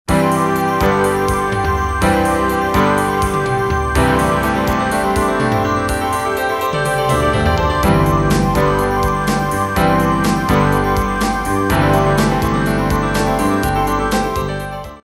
Contemporary Music Samples
Contemporary 89a